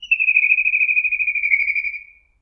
카이츠부리2
little_grebe2.mp3